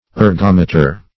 Ergometer \Er*gom"e*ter\, n. [Gr.